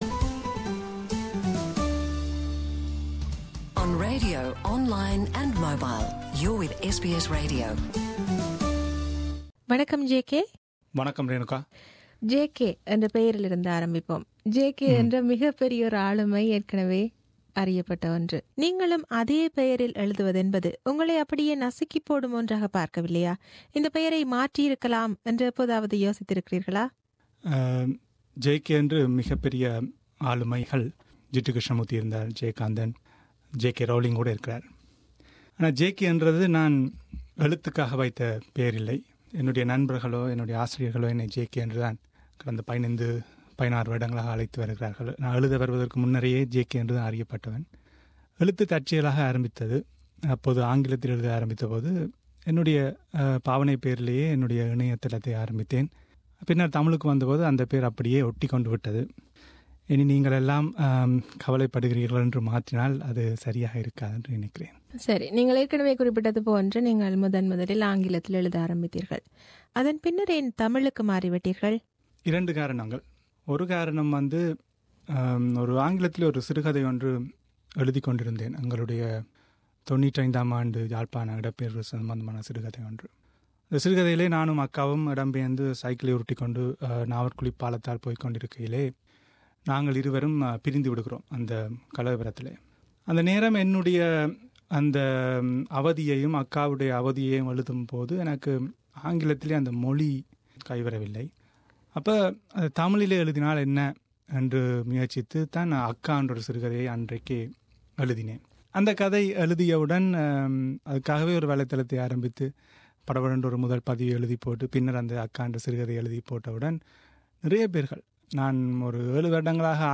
ஒரு சந்திப்பு